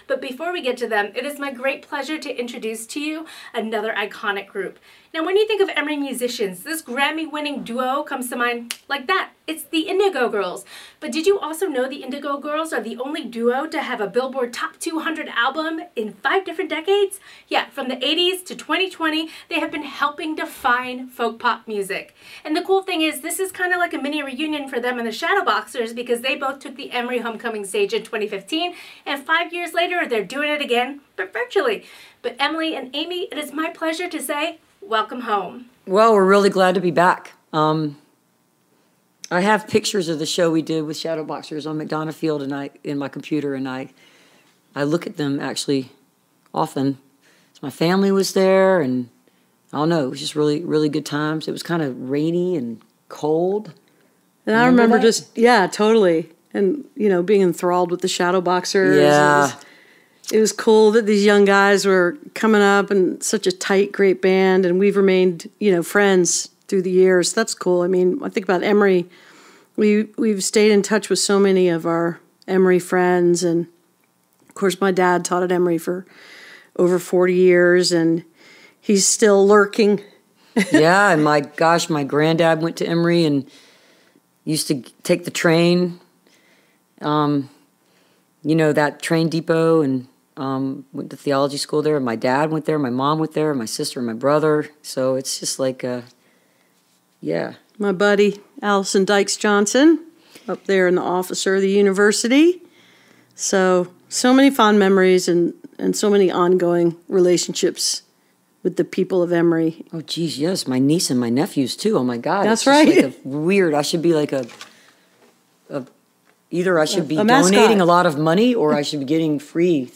(captured from the youtube livestream)
02. interview (indigo girls) (2:15)